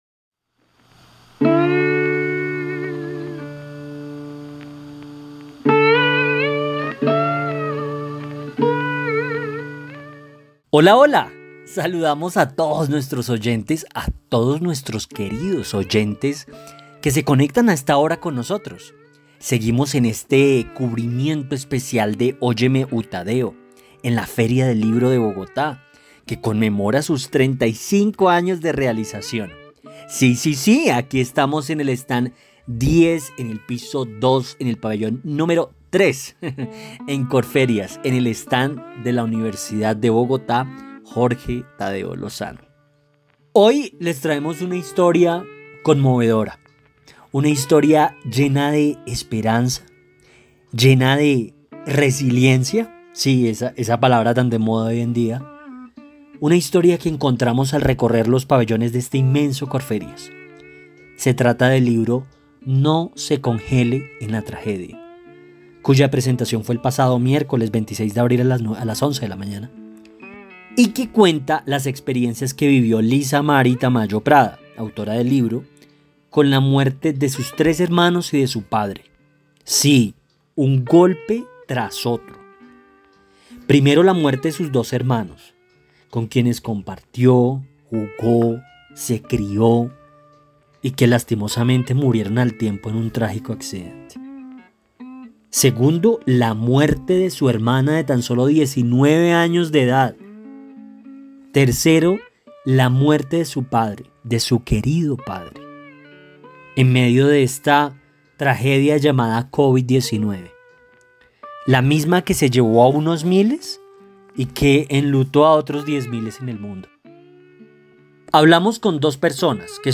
AUDIO ENTREVISTA: